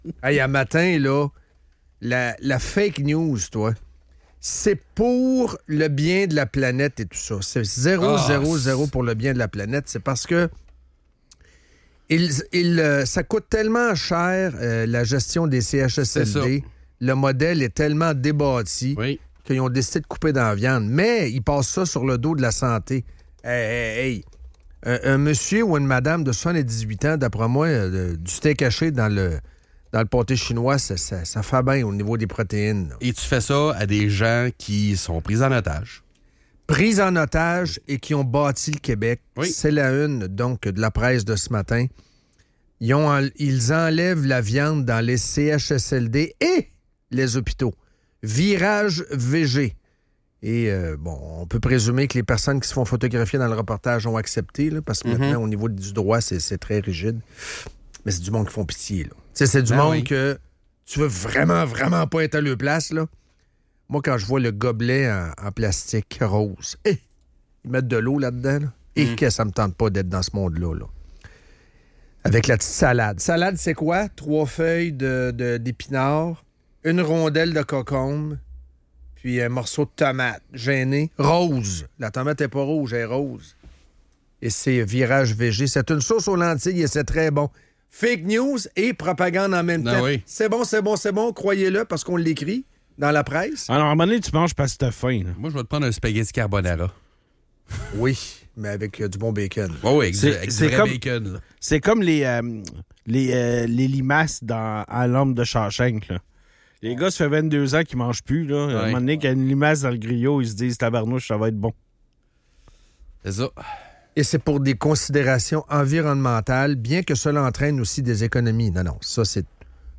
La décision de retirer la viande des menus dans les CHSLD et hôpitaux est dénoncée comme une mesure économique déguisée en souci environnemental. Les animateurs critiquent ce virage vers un régime végétarien imposé, arguant que cela nuit à la qualité de vie des aînés qui méritent de meilleures options alimentaires.